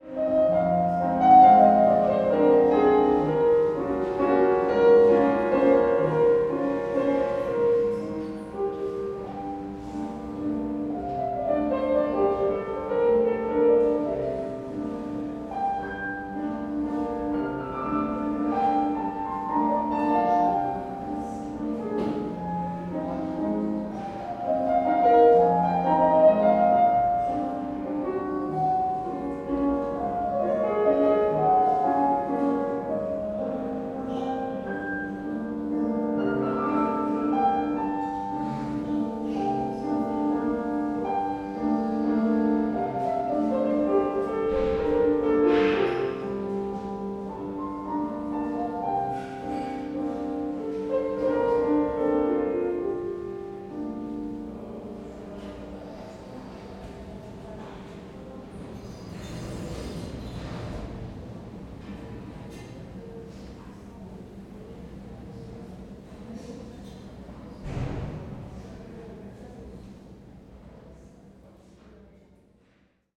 bruits de couloir | l'audio journal
Metz, Opéra Théâtre - KM140 ORTF / SXR4+
couloir_opera.mp3